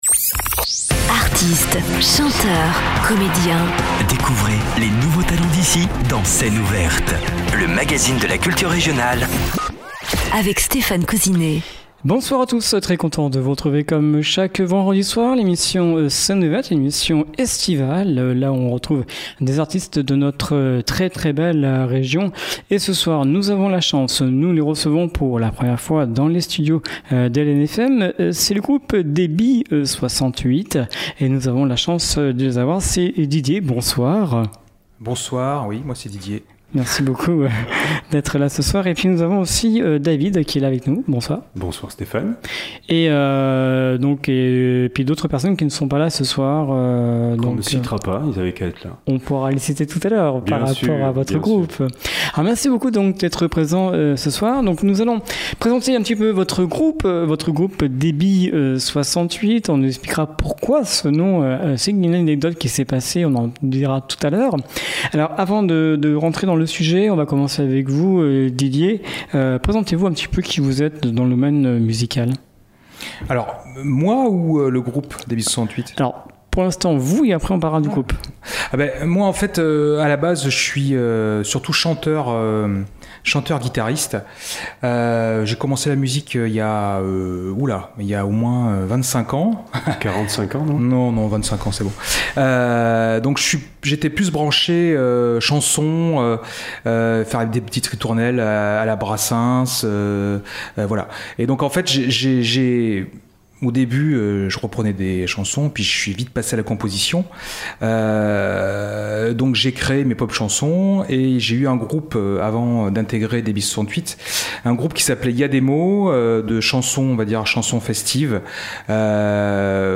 Voix, guitares, basse, batterie
et qui envoie du rock français alternatif et saturé